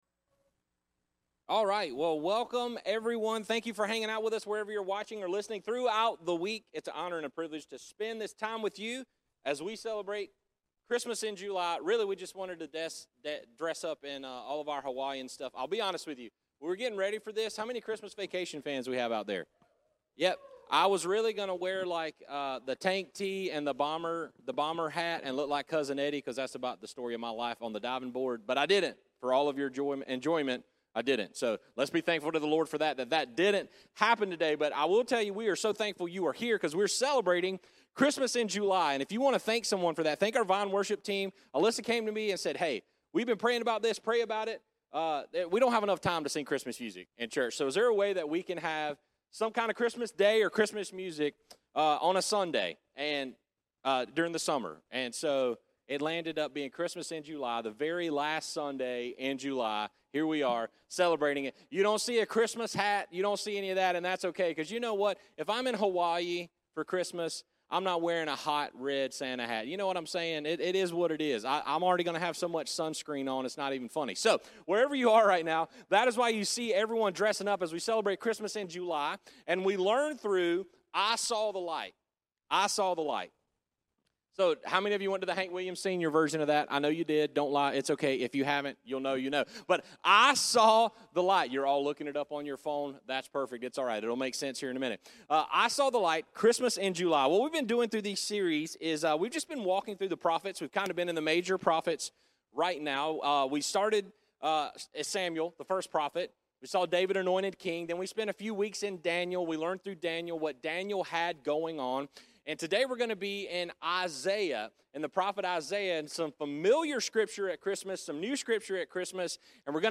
Sermons | The Vine Church